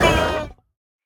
minecraft / sounds / mob / sniffer / hurt2.ogg
hurt2.ogg